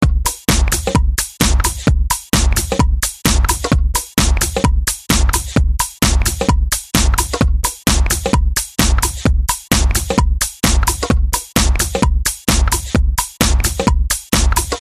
描述：悍马车.50口径的枪反复射击。
标签： 半英寸 2.50 口径 悍马
声道立体声